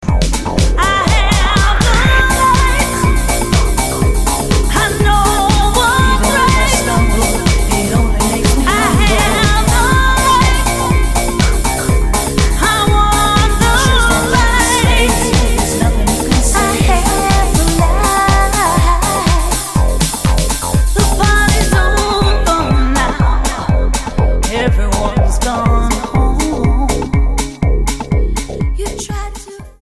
STYLE: Pop
With a strong and passionate voice, rich and full